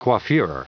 Prononciation du mot coiffure en anglais (fichier audio)
coiffure.wav